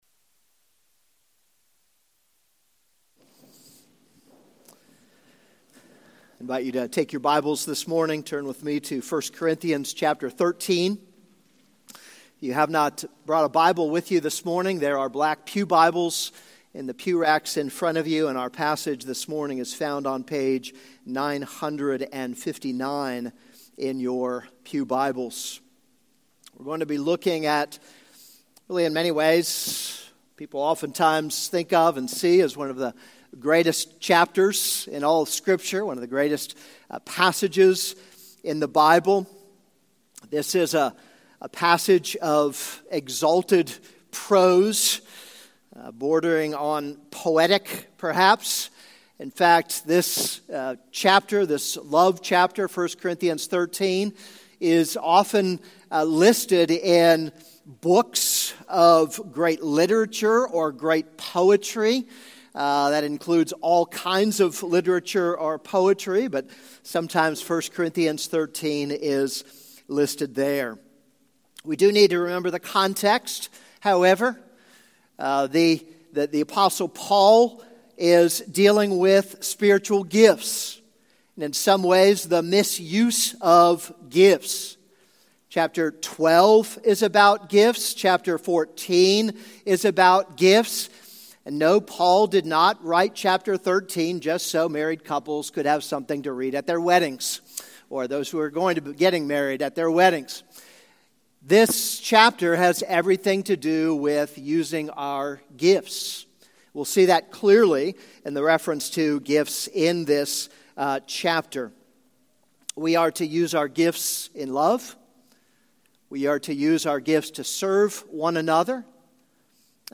This is a sermon on 1 Corinthians 13:1-13.